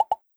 GenericNotification10b.wav